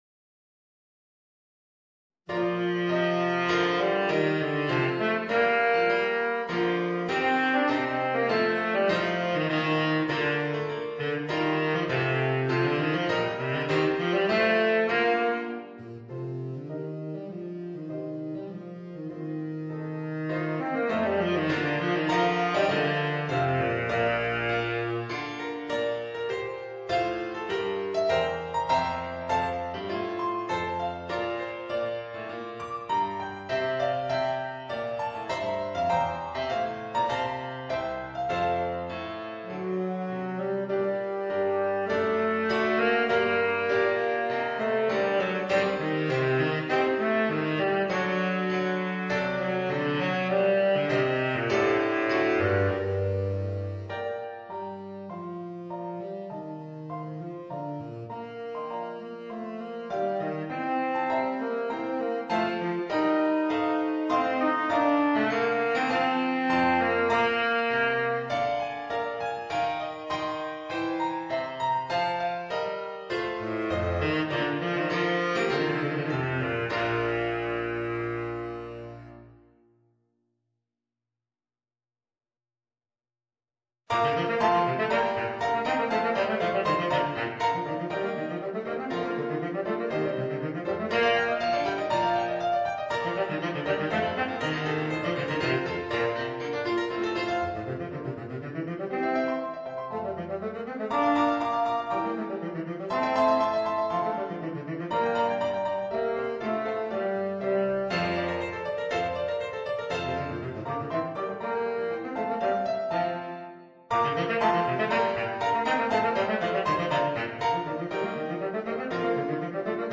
Voicing: Baritone Saxophone and Organ